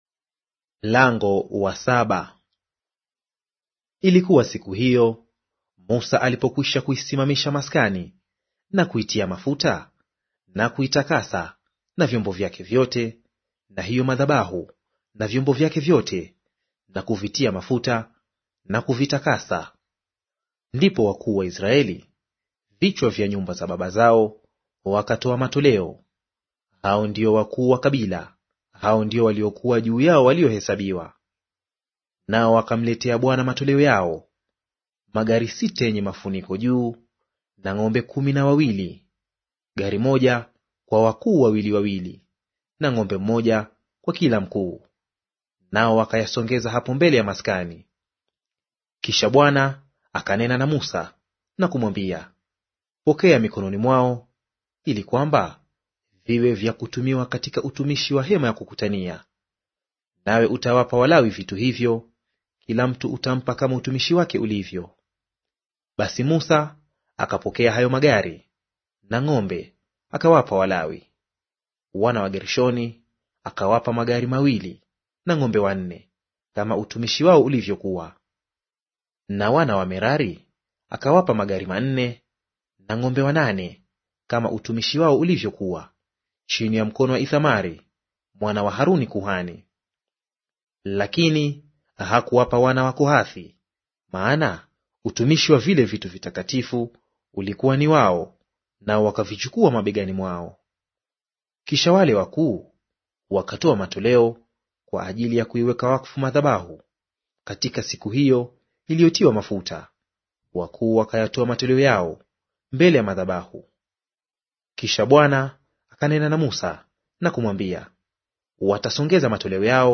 Audio reading of Hesabu Chapter 7 in Swahili